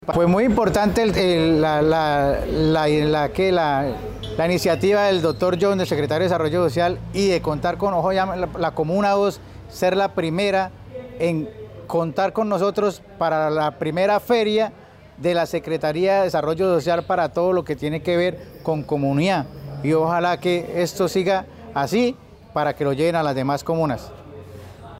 Descargue audio: John Pabón, secretario de Desarrollo Social de Bucaramanga / Francisco Cáceres, presidente de los ediles de la Comuna 2